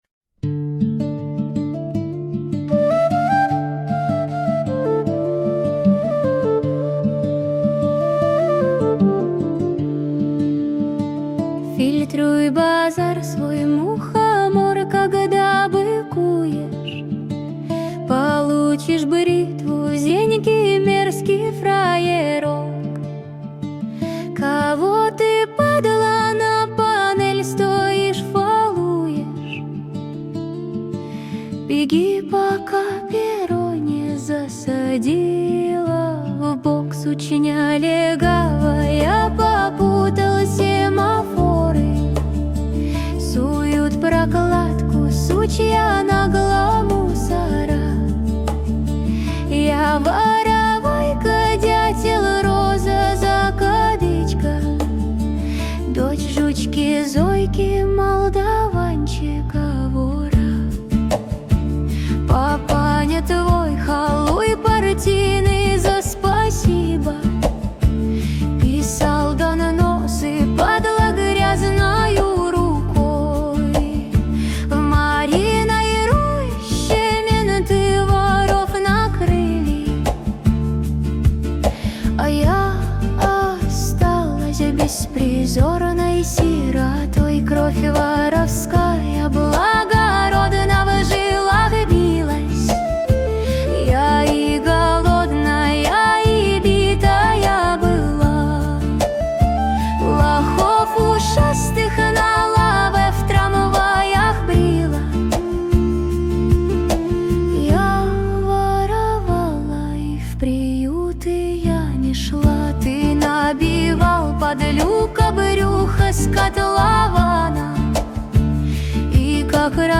Качество: 320 kbps, stereo
Поп музыка